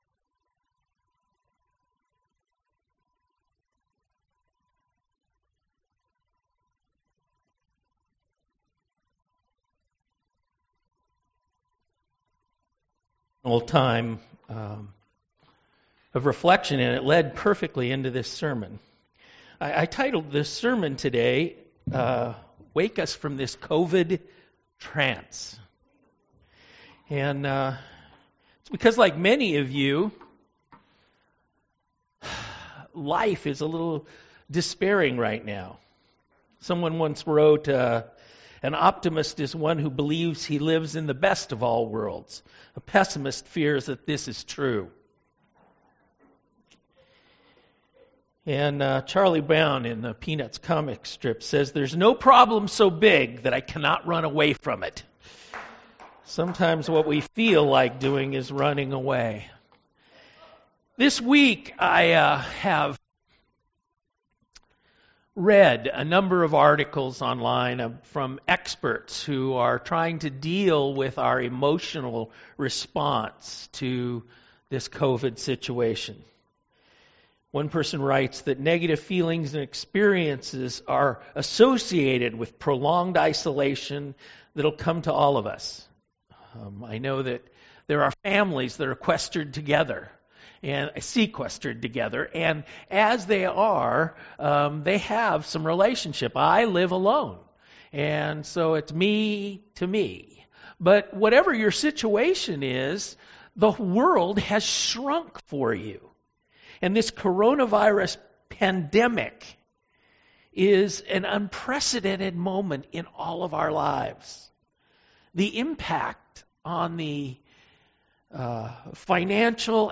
Sermon Audio Archives | Church of Newhall